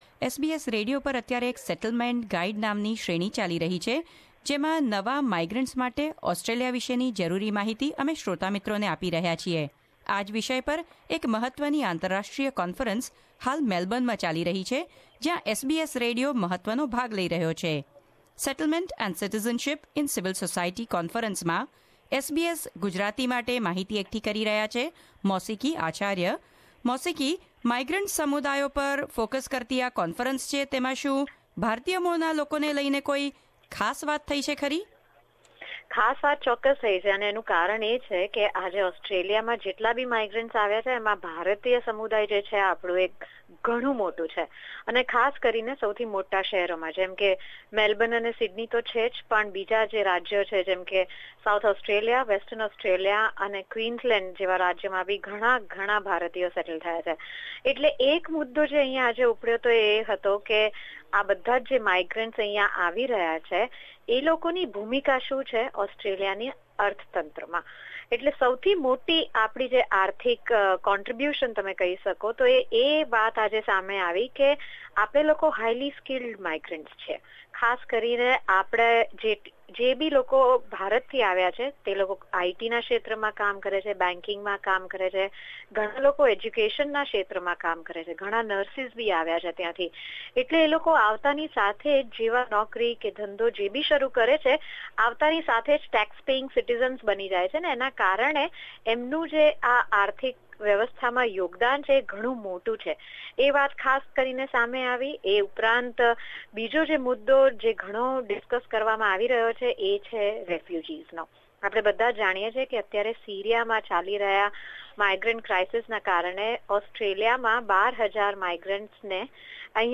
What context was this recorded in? Second day of SCOA conference Source: Settlement Council of Australia